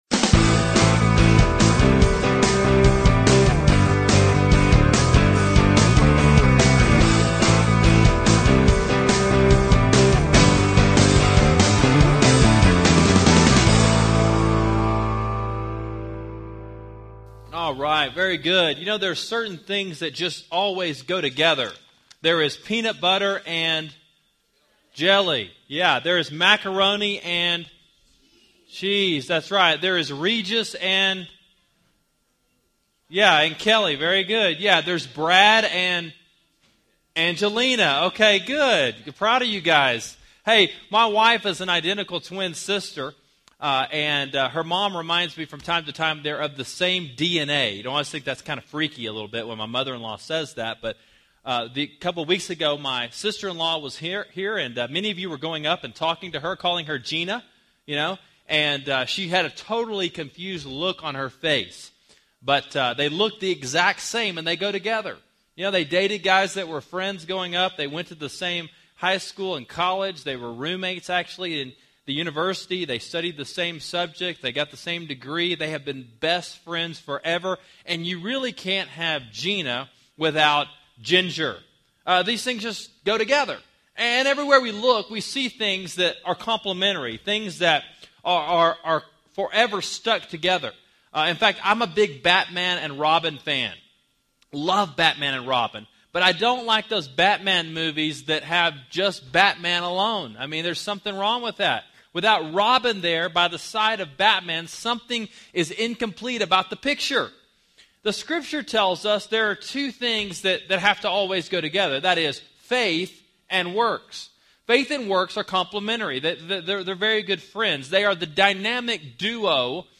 Relevant Faith: The Dynamic Duo, James 2:14-26 – Sermon Sidekick